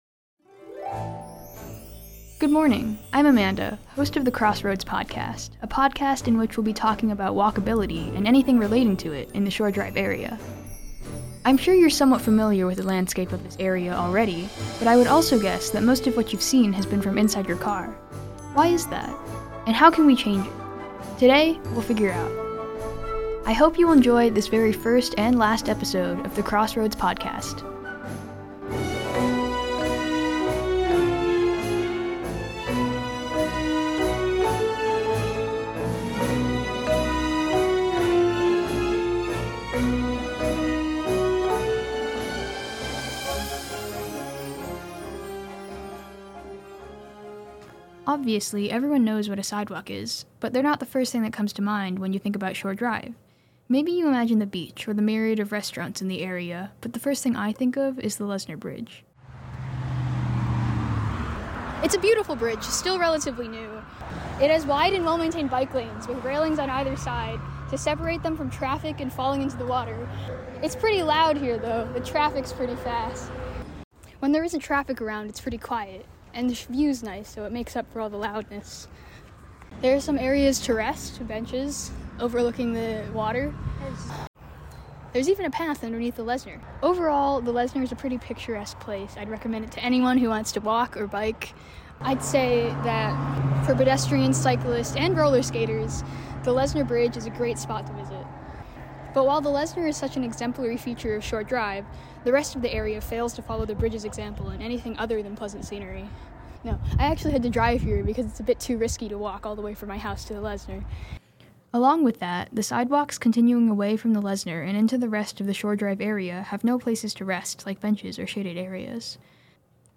She interviews pedestrians and bicycle riders on Shore Dr.